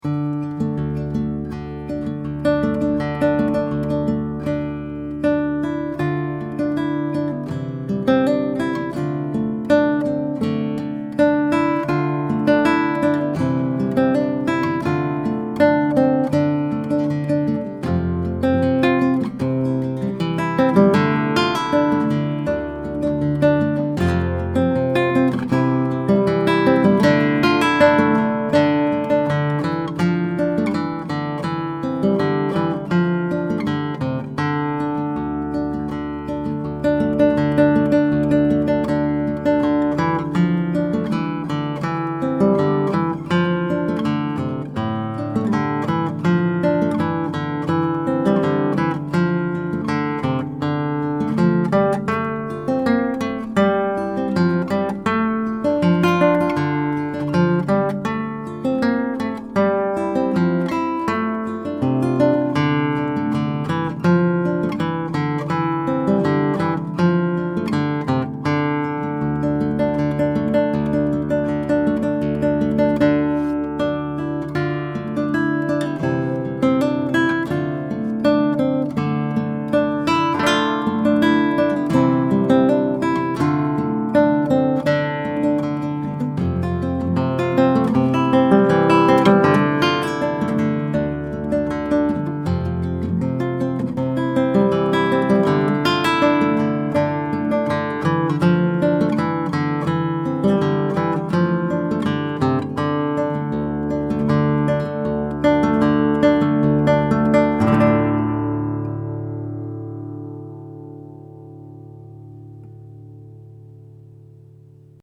It has bass roll-off to compensate for proximity effect, and a slight HF bump.
Here are a few quick, 1-take MP3 sound files to give you an idea of what to expect. These MP3 files have no compression, EQ or reverb -- just straight signal, tracked into a Presonus ADL 600 preamp to an Apogee Rosetta 200 A/D converter into Logic.
RAMIREZ CLASSICAL HARP GUITAR